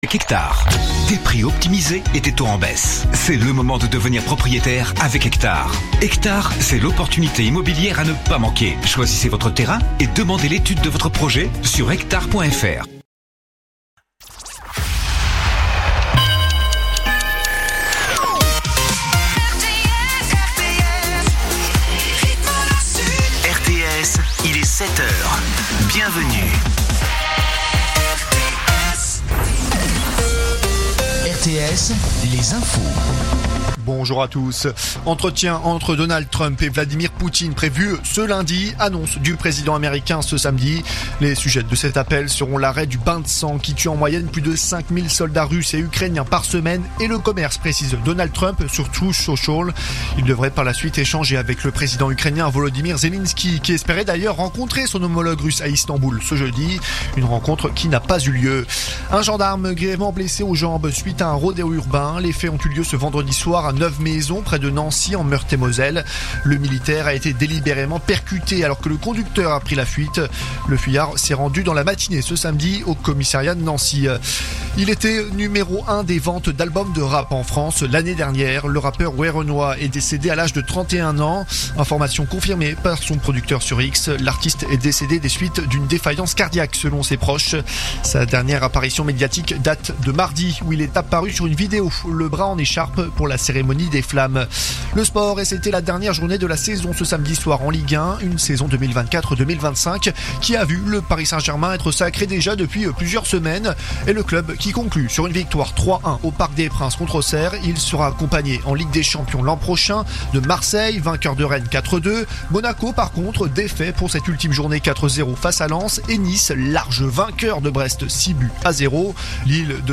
Écoutez les dernières actus de Nîmes en 3 min : faits divers, économie, politique, sport, météo. 7h,7h30,8h,8h30,9h,17h,18h,19h.